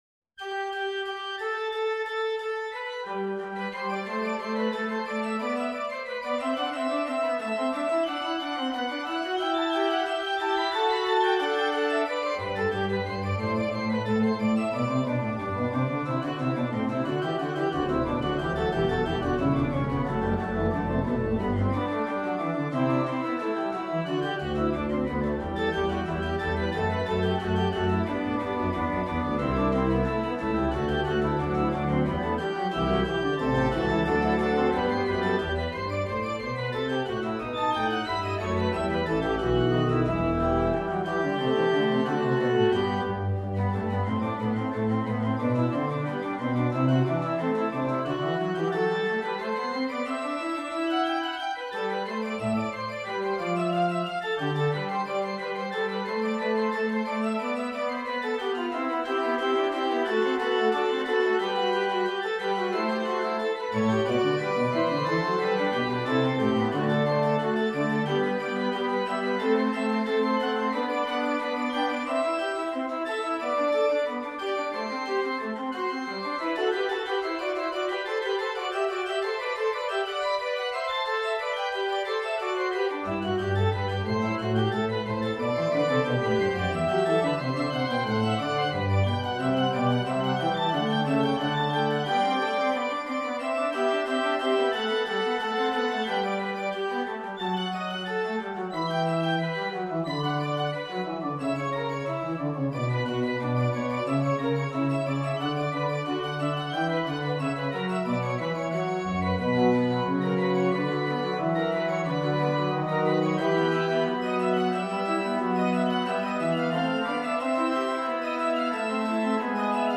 Organ  (View more Advanced Organ Music)
Classical (View more Classical Organ Music)